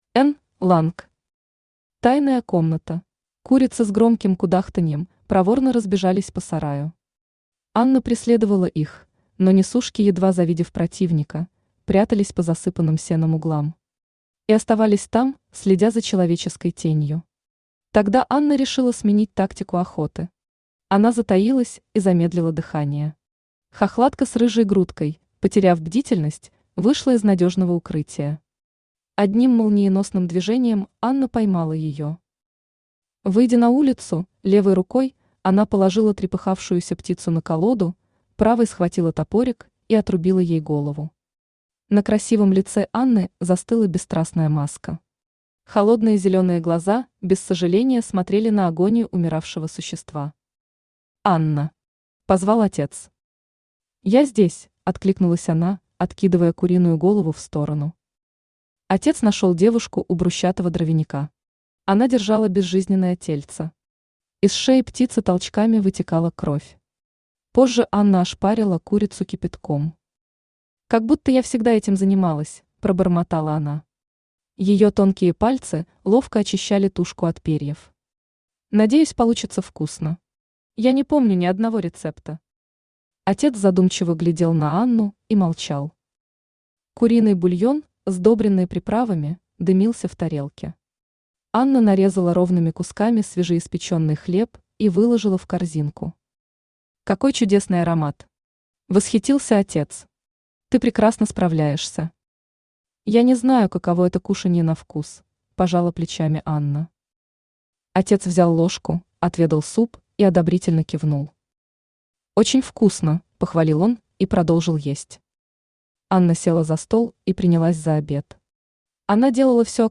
Аудиокнига Тайная комната | Библиотека аудиокниг
Aудиокнига Тайная комната Автор Н. Ланг Читает аудиокнигу Авточтец ЛитРес.